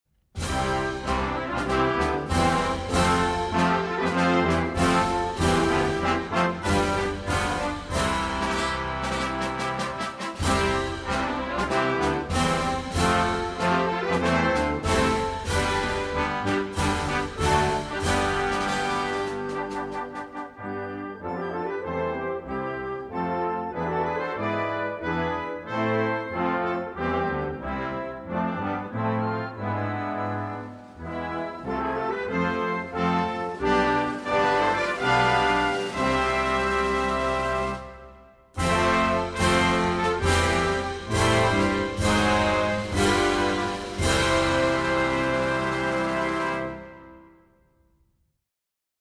National_Anthem_of_Dominica_by_US_Navy_Band.mp3